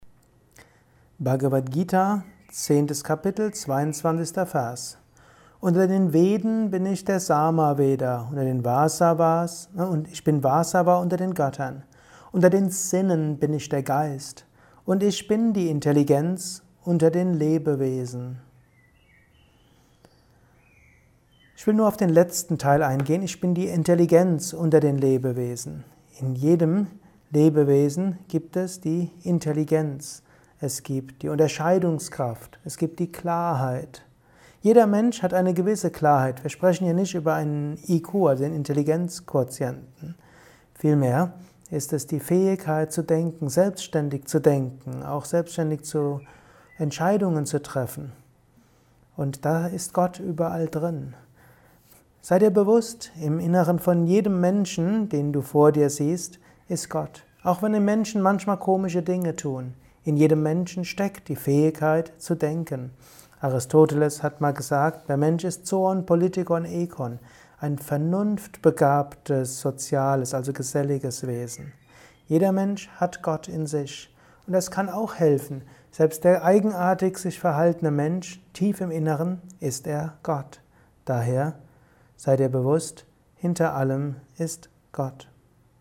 Kurzvorträge